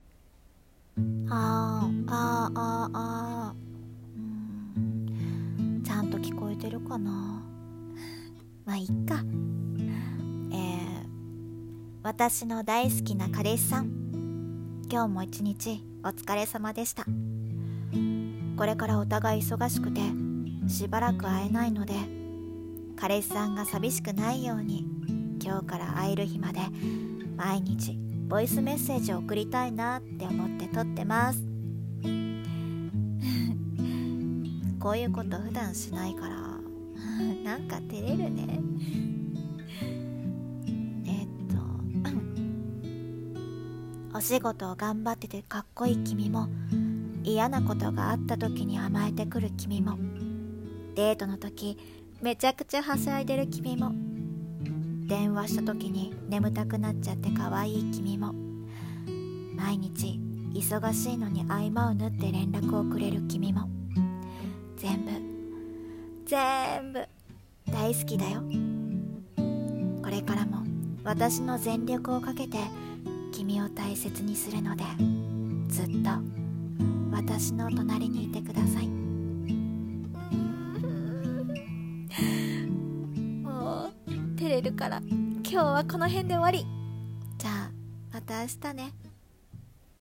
【声劇】ボイスメッセージ
癒し